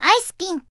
Added Zundamon voicepack